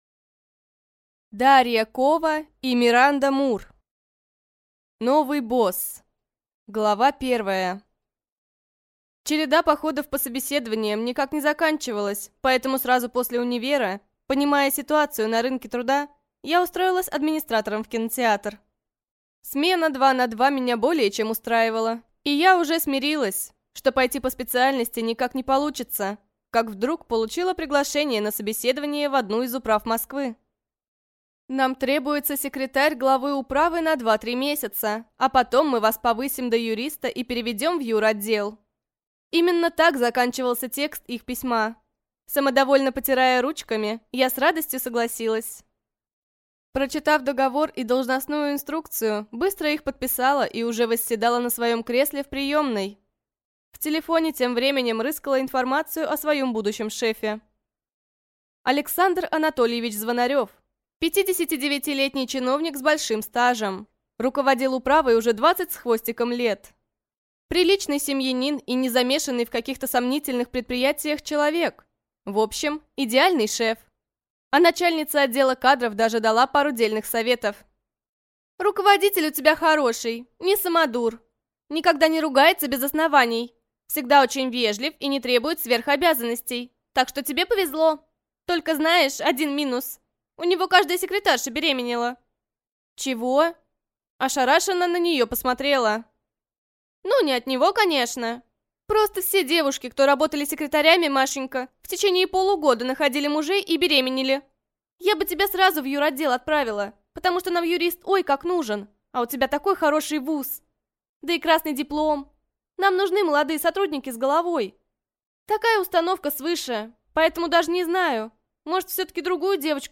Аудиокнига Новый босс | Библиотека аудиокниг